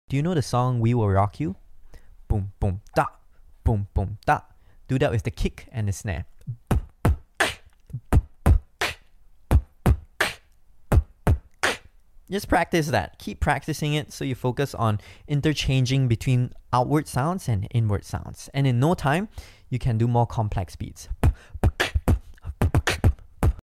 Basic beat for beatbox beginners